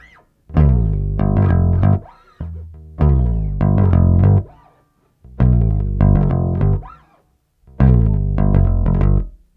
SONS ET LOOPS GRATUITS DE BASSES DANCE MUSIC 100bpm
Basse dance 5